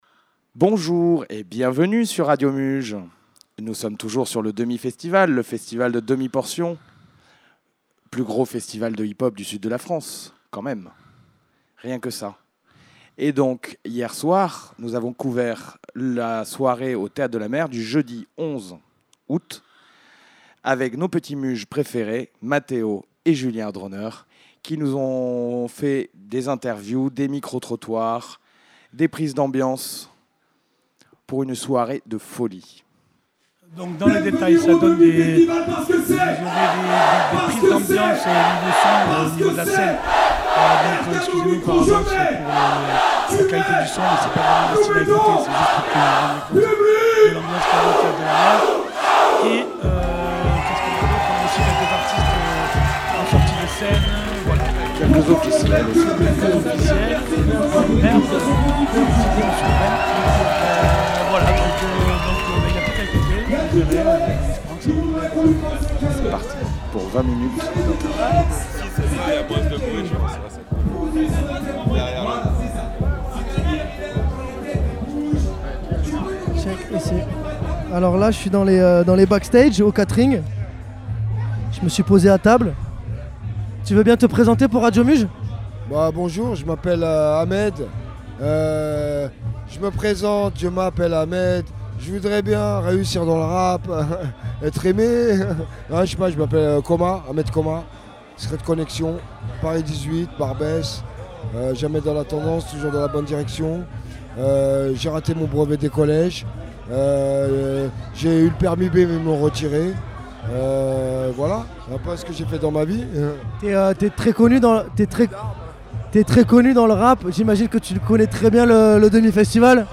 ITW & Micro-Trottoirs